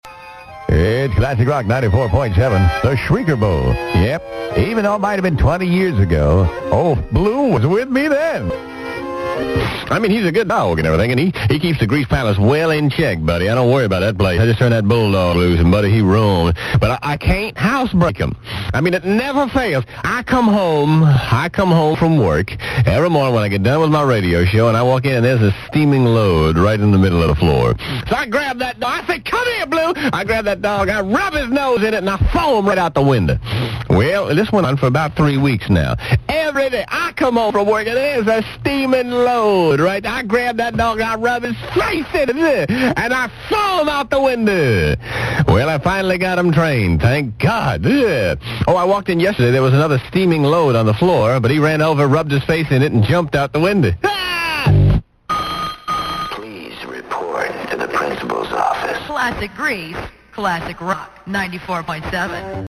On January 30th, 1999 (the day before the Super Bowl, get it?), Classic Rock 94.7 played a collection of classic bits, including some from Grease's early days on Florida radio in the 70's for the first time in 20 years!